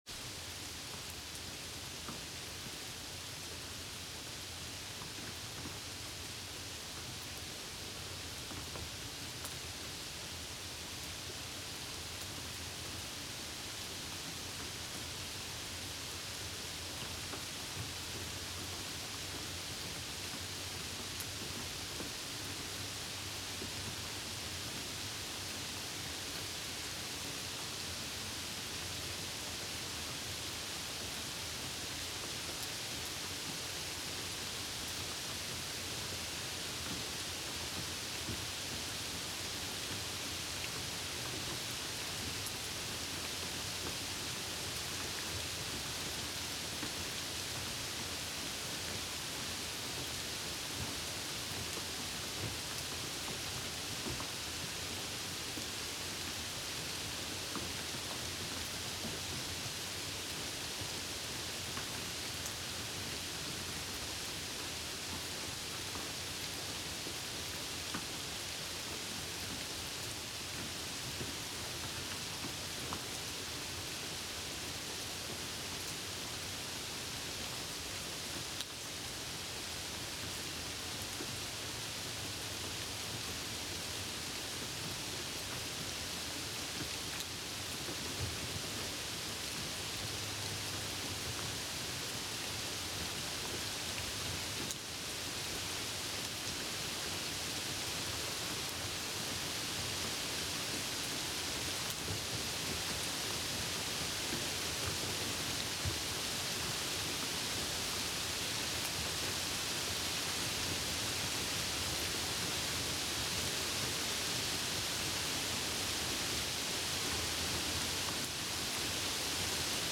Index of /Relaxing/Nature/Rain/